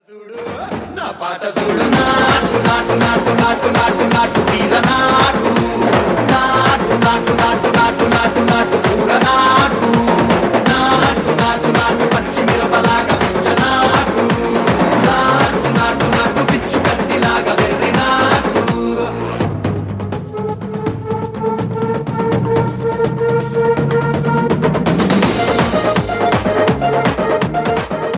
Its energetic beat is perfect for a fun ringtone.